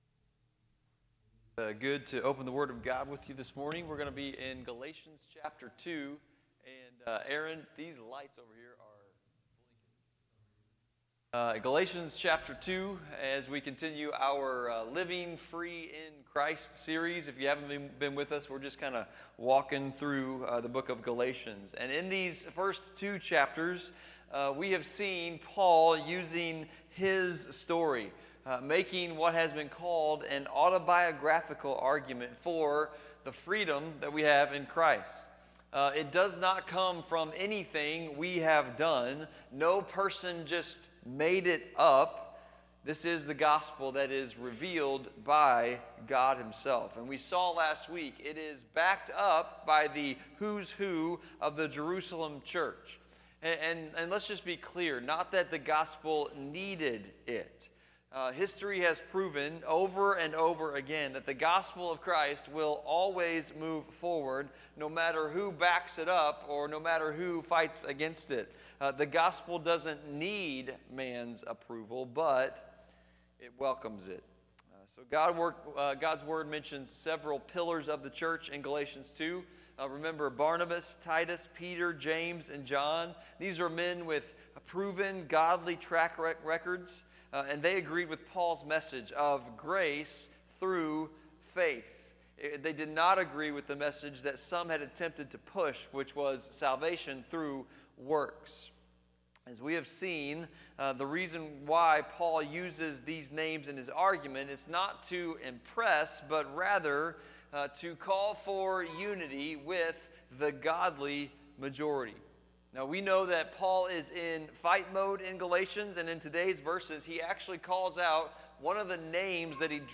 Sermons by FBC Potosi